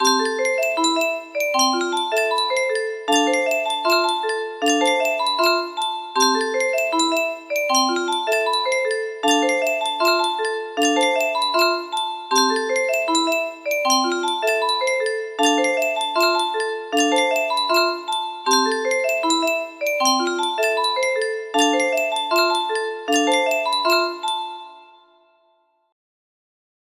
xmas2 music box melody